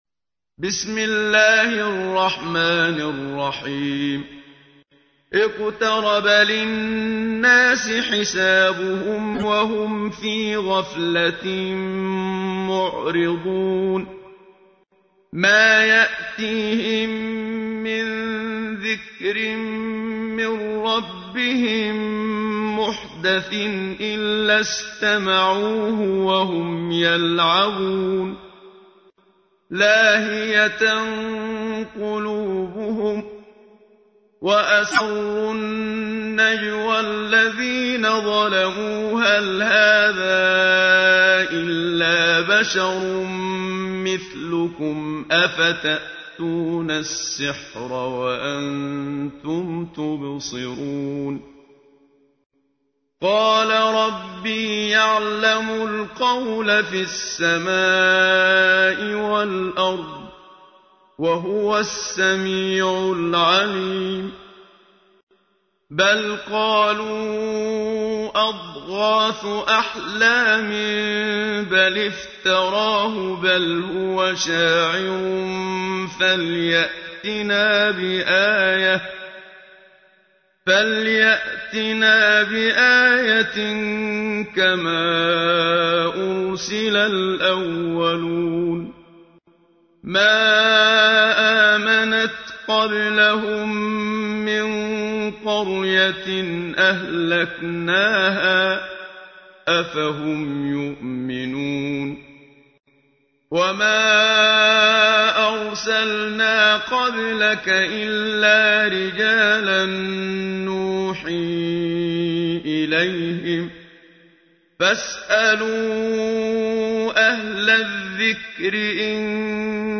تحميل : 21. سورة الأنبياء / القارئ محمد صديق المنشاوي / القرآن الكريم / موقع يا حسين